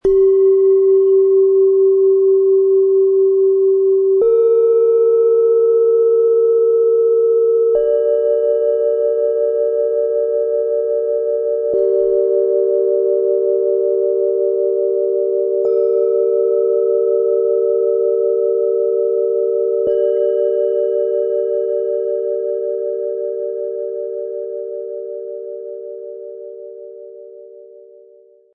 • 3 Planetenschalen (Tageston · Mond · Mars)
Tiefster Ton - Tageston | Zentriert ankommen
Mittlerer Ton - Mond | Gefühle wahrnehmen
Höchster Ton - Mars | Fokussiert umsetzen
In unserem Sound-Player - Jetzt reinhören können Sie den Original-Klang dieses Planetenschalen-Sets direkt anhören. So bekommen Sie ein realistisches Gefühl dafür, wie die Schalen gemeinsam wirken und wie klar, ruhig und fokussiert der Gesamtklang ist.
Bengalen Schale, Schwarz-Gold, 13 cm Durchmesser, 6,9 cm Höhe